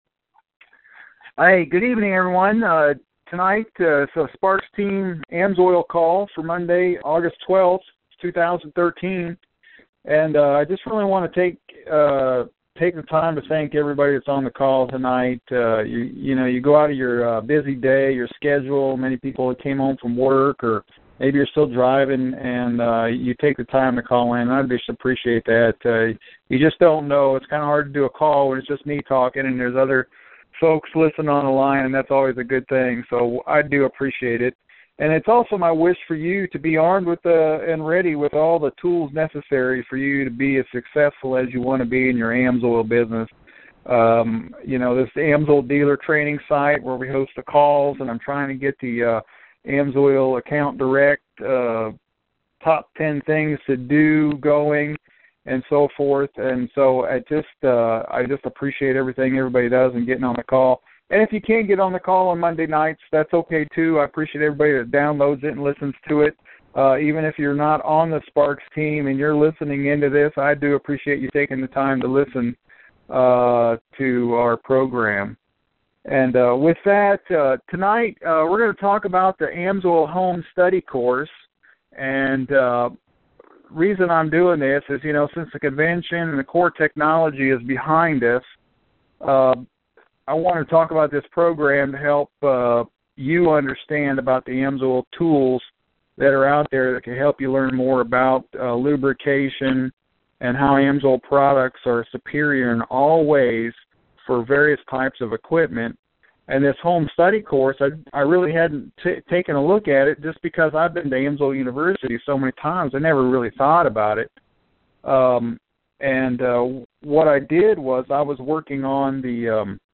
AMSOIL Dealer training call talking the AMSOIL Home Study Course